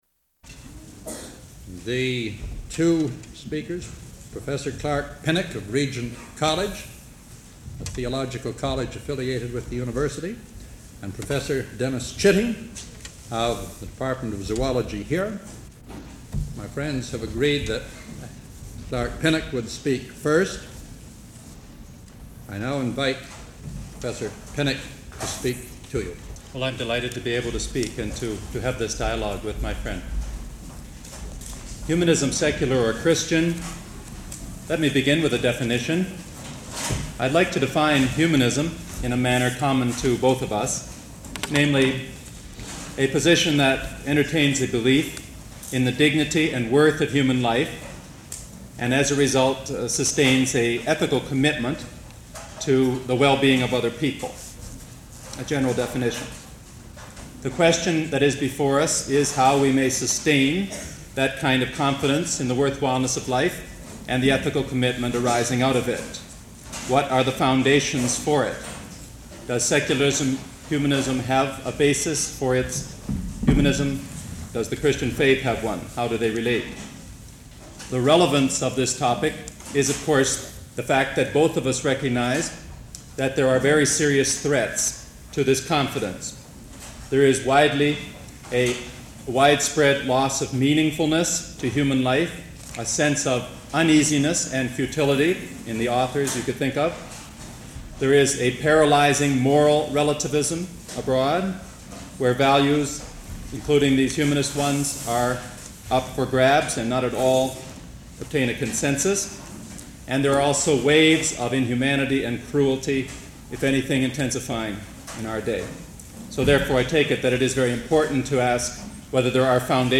Recording of a debate